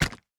Drop Stone A.wav